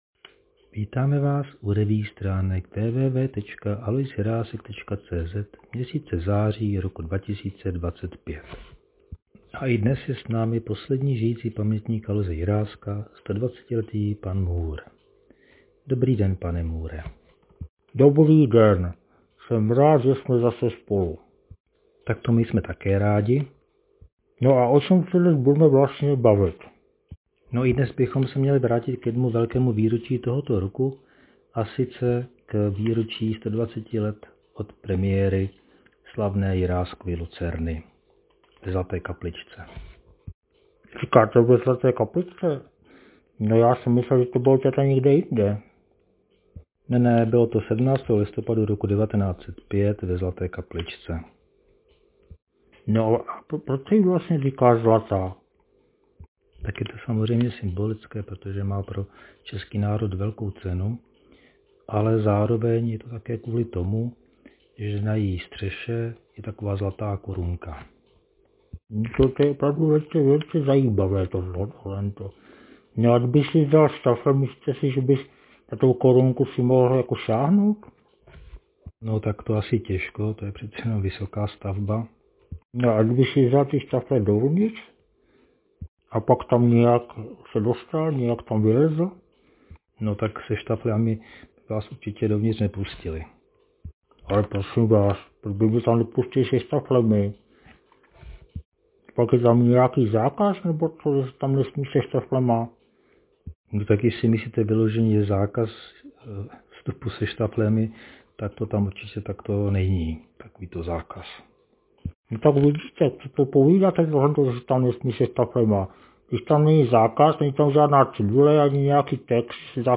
Další amatérský pokus o zvukovou revue se s obvyklou humornou nadsázkou již potřetí vrací k letošnímu významnému výročí – 17.listopadu 1905 proběhla ve Zlaté kapličce slavná premiéra jedné z nejoblíbenějších a nejhranějších českých divadelních her, Jiráskovy Lucerny.